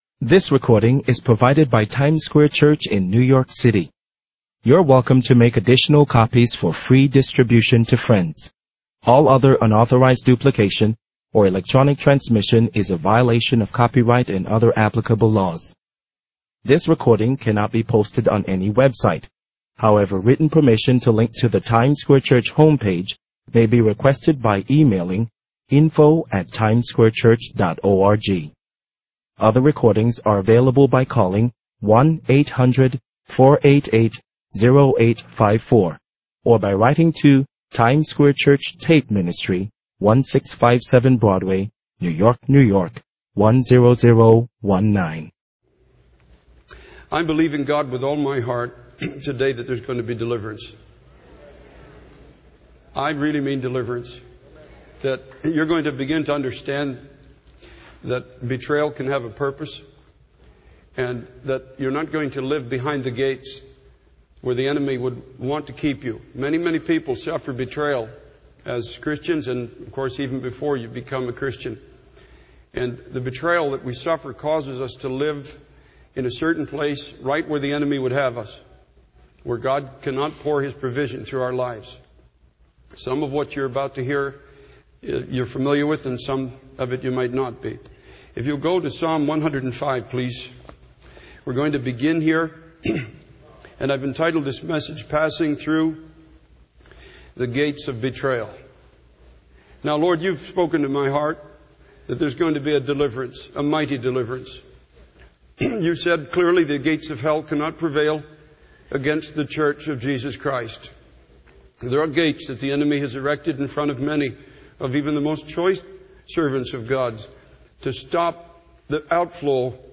In this sermon, the speaker emphasizes the importance of understanding that God is in control of everything that happens in our lives. He uses the story of Joseph from the book of Genesis to illustrate this point.